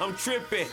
SouthSide Chant (68)(1).wav